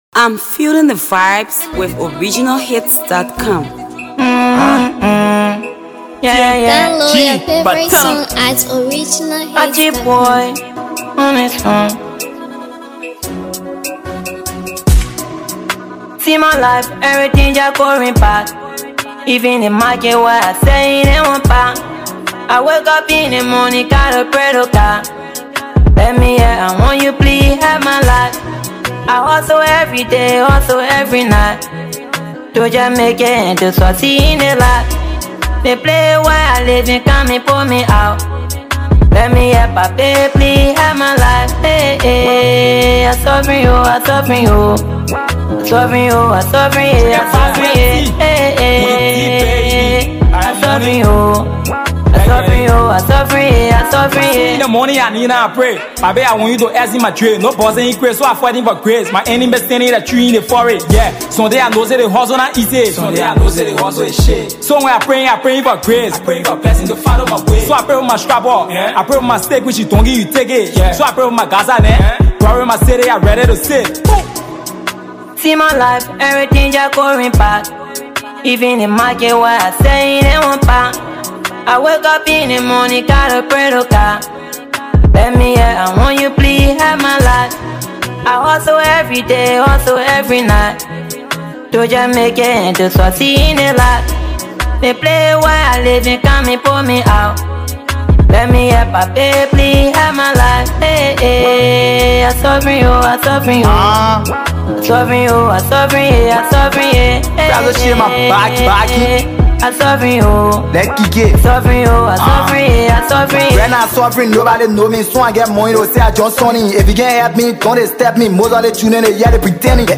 studio effort